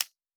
Fantasy Interface Sounds
UI Tight 22.wav